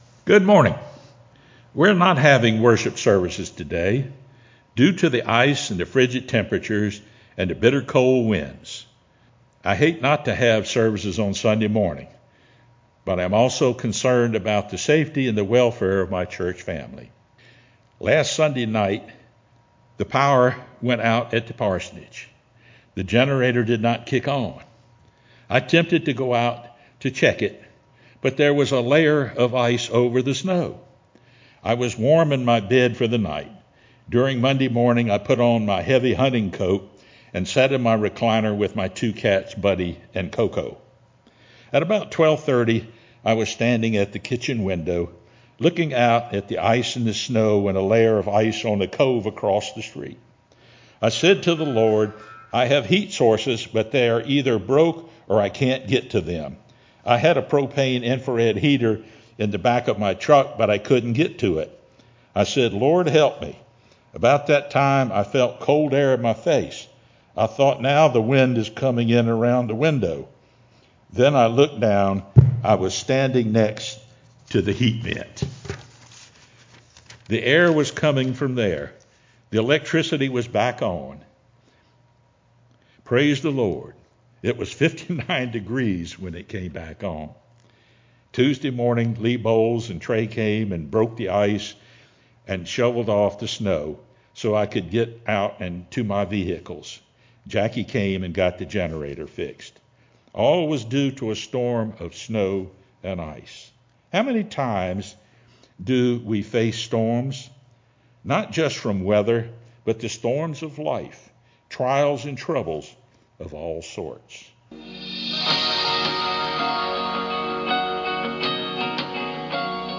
sermonFeb01-CD.mp3